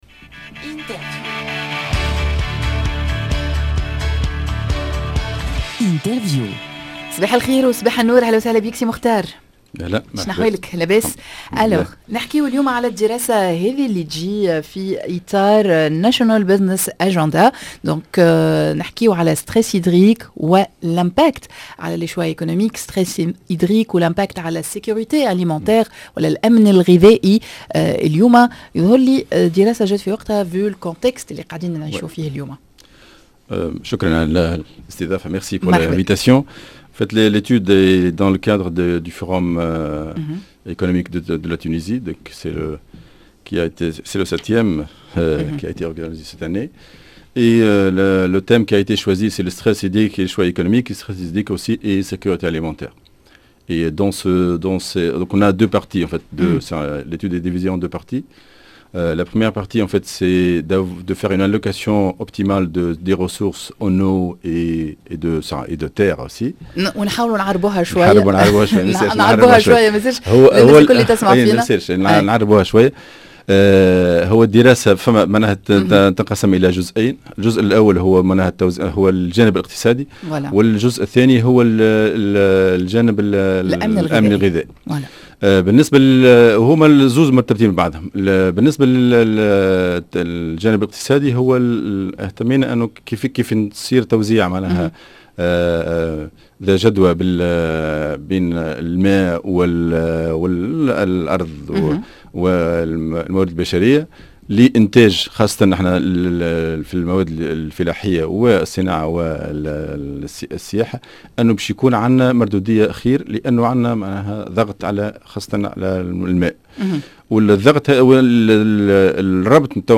دراسة عن الشح المائي و الأمن الغذائي #l'interview